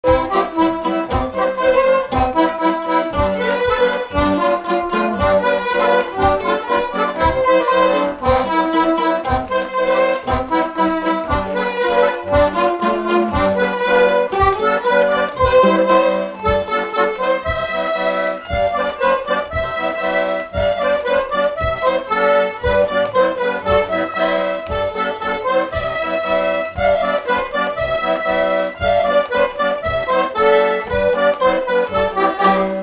Instrumental
danse : polka
Pièce musicale inédite